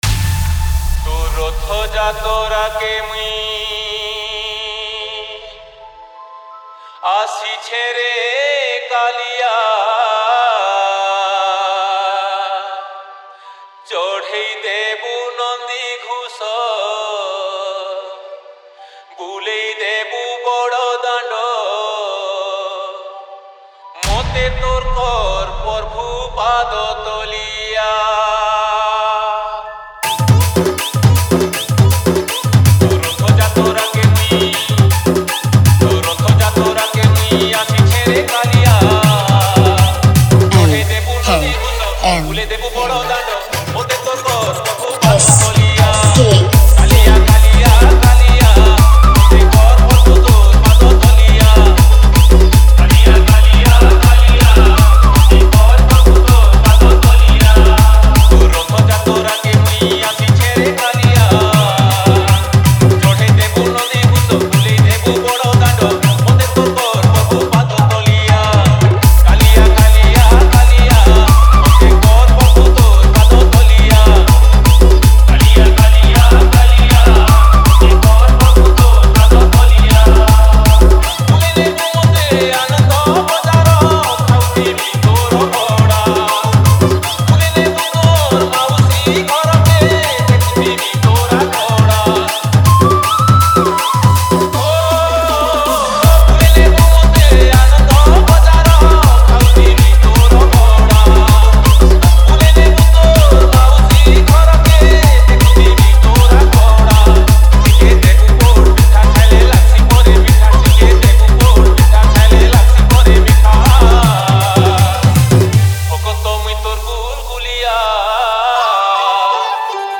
Bhajan Dj Song Collection 2022 Songs Download